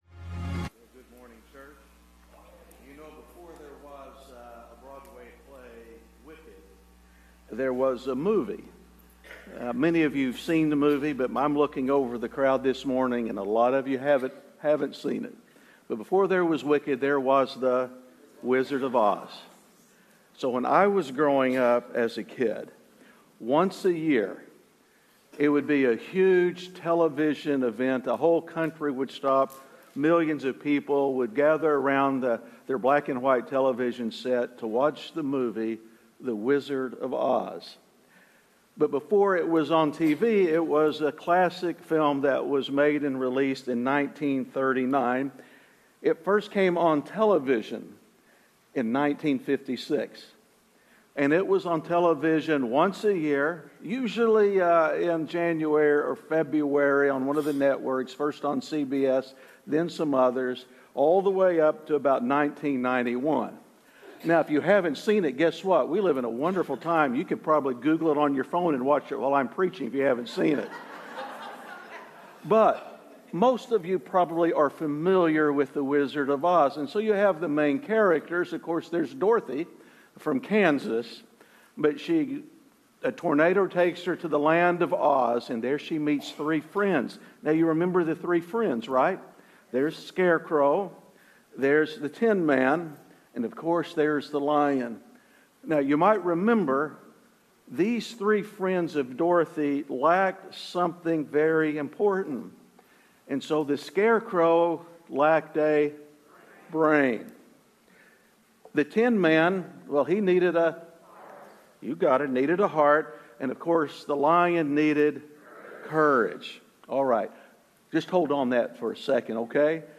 Missed a Sunday? You can go back and catch up on any of the sermons you missed.